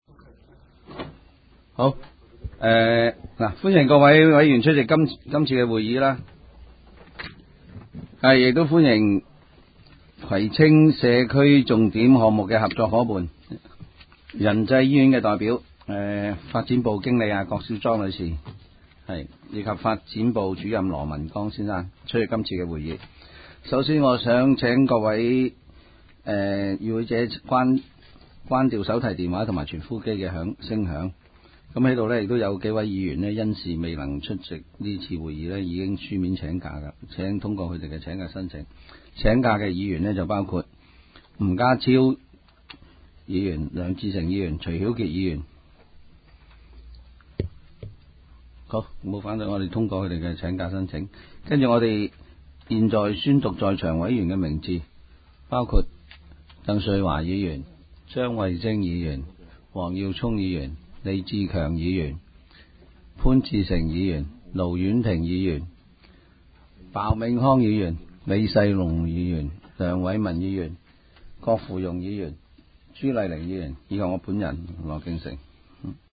委员会会议的录音记录
开会词